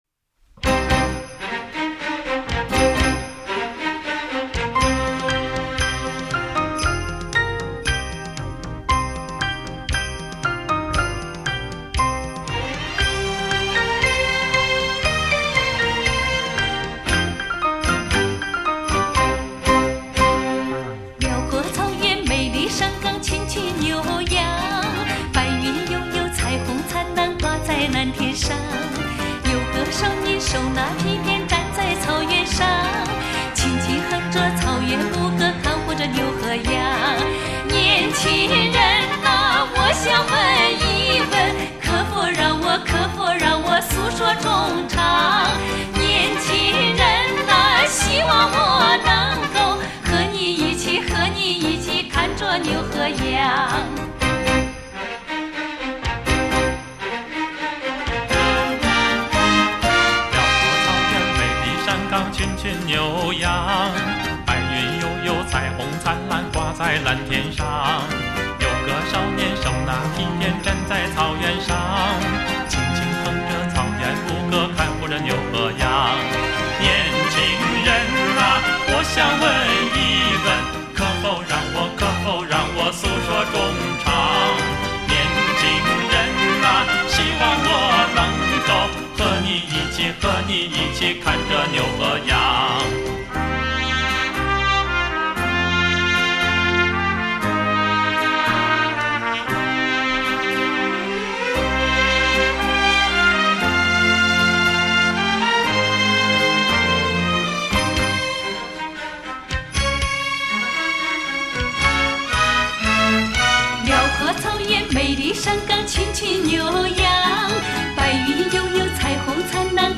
二人的合唱尤其和谐、动听，极具魅力成为八十年代以来二人组